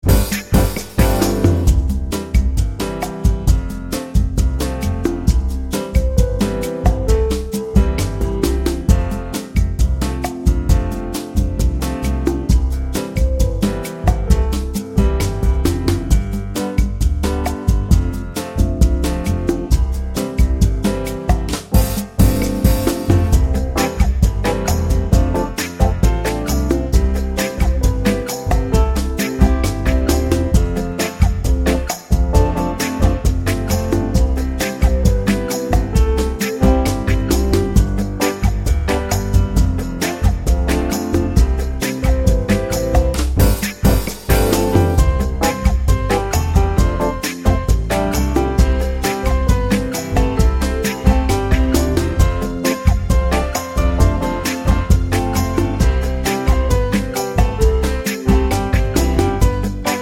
Down 4 Semitones